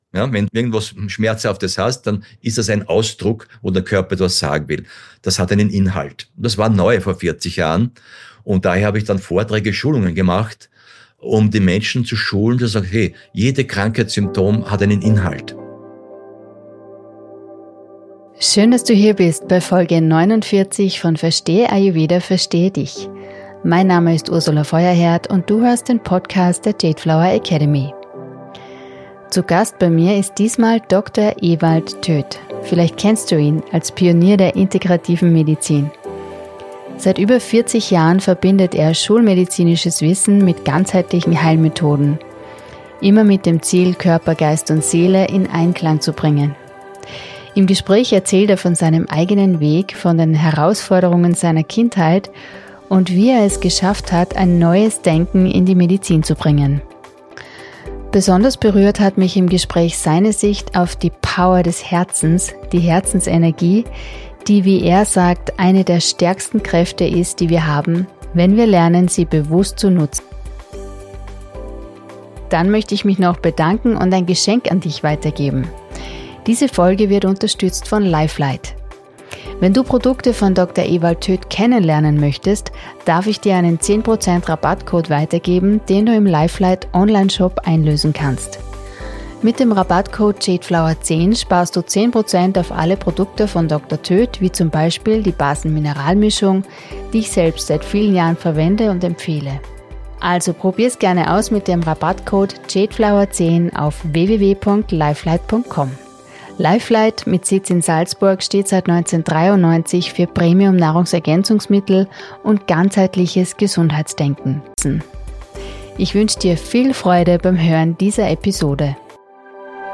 **Darüber sprechen wir im Interview:**